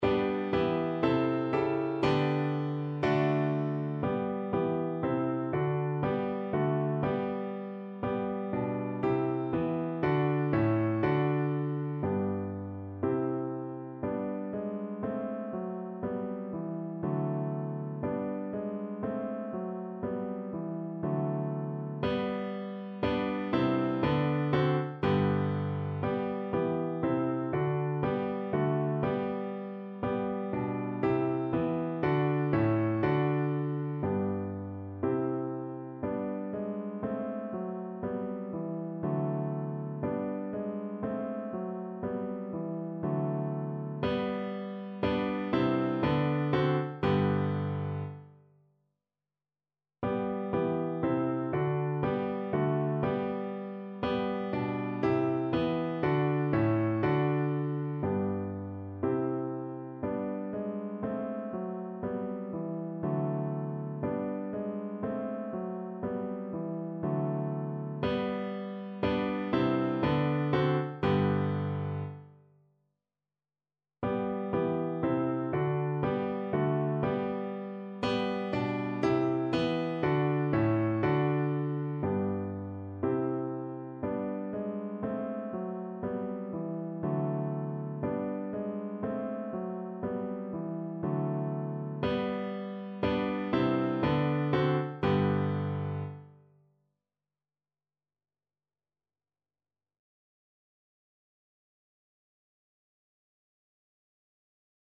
kolęda: Anioł pasterzom mówił (na wiolonczelę i fortepian)
Symulacja akompaniamentu
koleda_nuty_aniol-pasterzom-mowil_vc-pf-acc..mp3